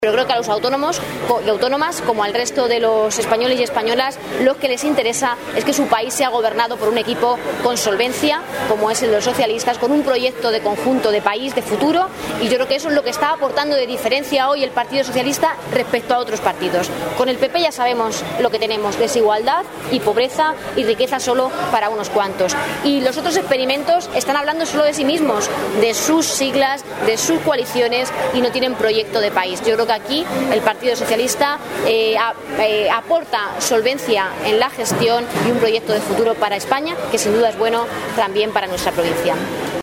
Durante su visita al mercado de abasto de Ciudad Real
Cortes de audio de la rueda de prensa